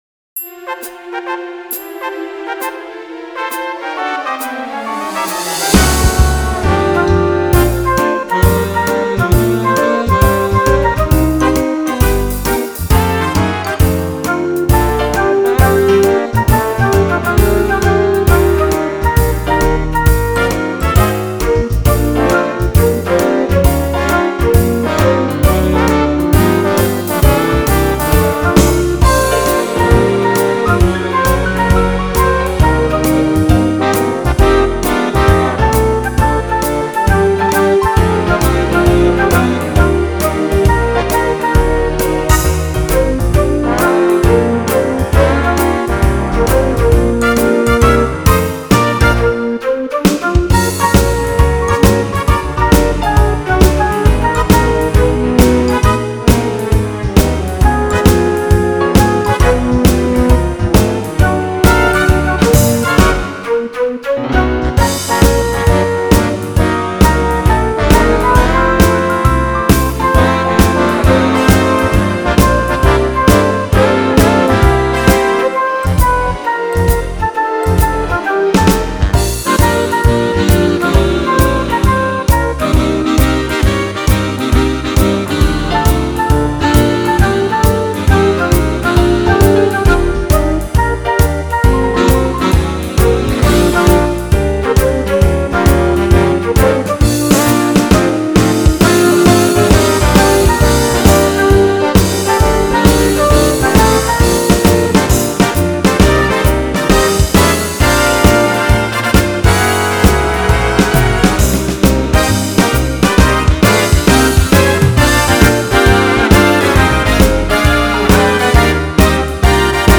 See how it tucks in so nicely under the melody line.